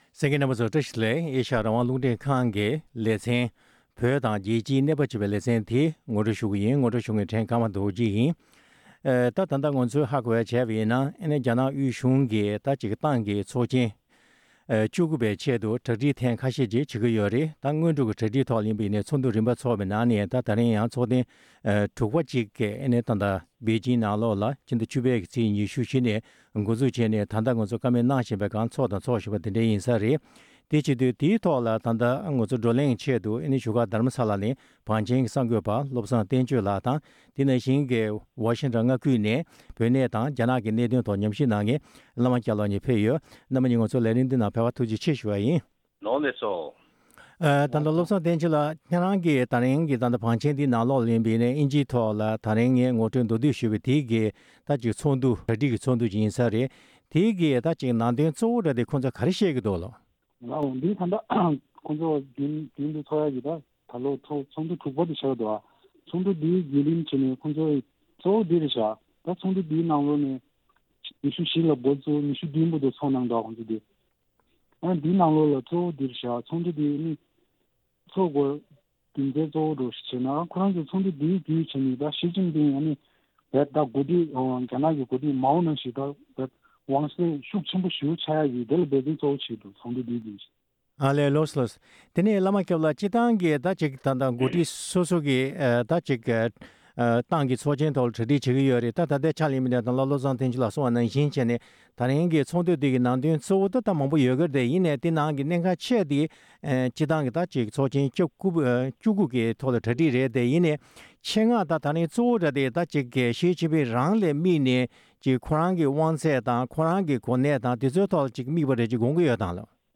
སྲིད་འཛིན་ཞི་ཅིང་ཕིང་གི་དབང་ཚད་ཤུགས་ཆེ་རུ་འགྲོ་བ་ཡིན་ན་དེས་བོད་དོན་ལ་ཤུགས་གང་འདྲ་ཞིག་ཡོད་མིན་ཐད་བགྲོ་གླེང་གནང་བ།